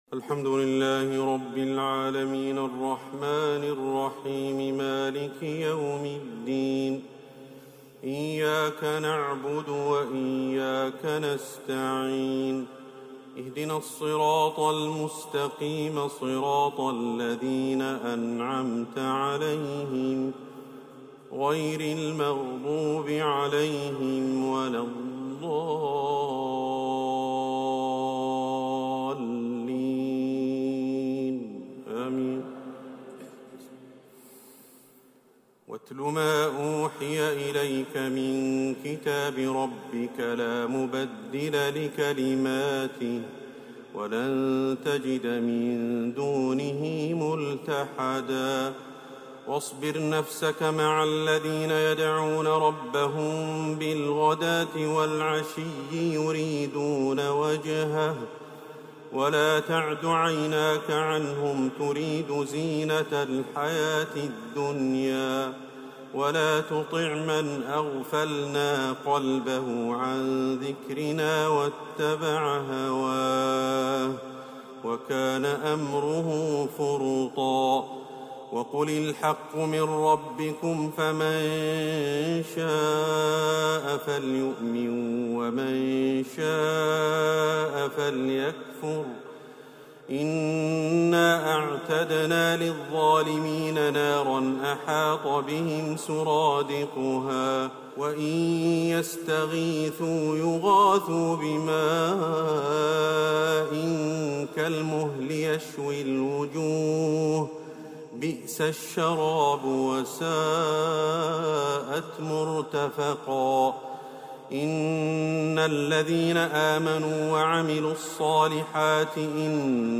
تراويح ليلة 20 رمضان 1447هـ من سورة الكهف (27-82) | Taraweeh 20th night Ramadan1447H Surah Al-Kahf > تراويح الحرم النبوي عام 1447 🕌 > التراويح - تلاوات الحرمين